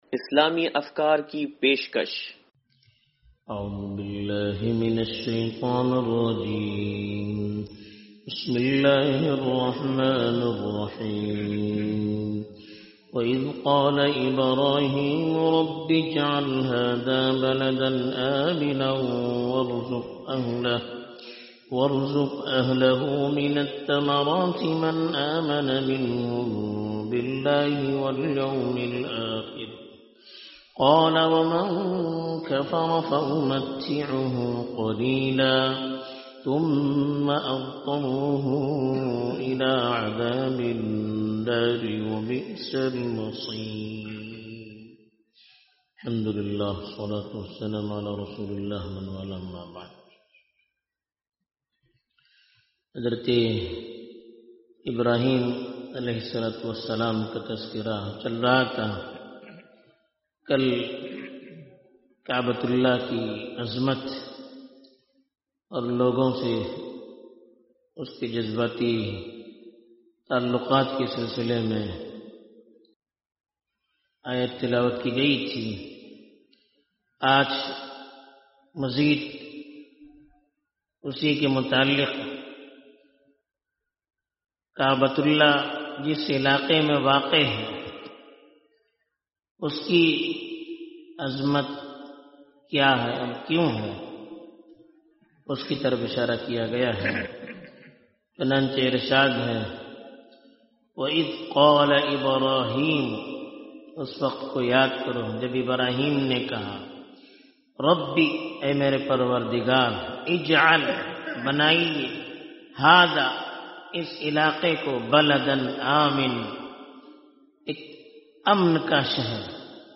درس قرآن نمبر 0088
درس-قرآن-نمبر-0088-2.mp3